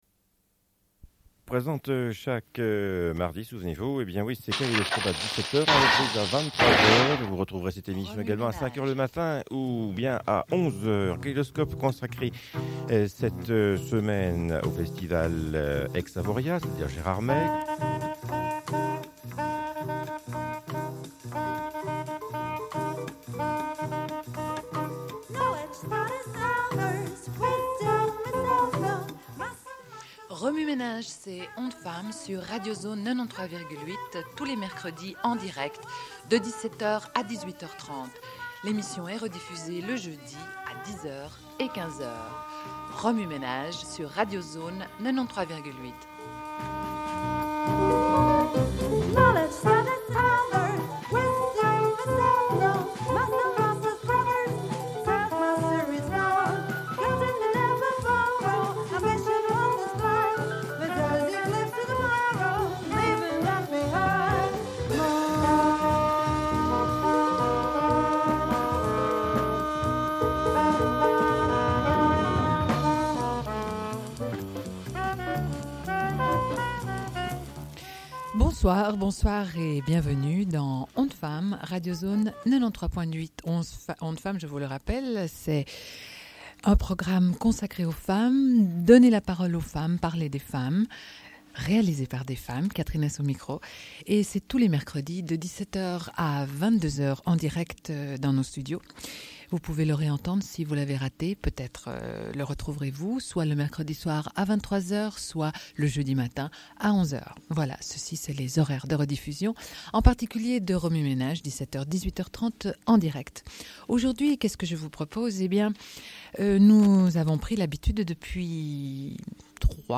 Une cassette audio, face A31:17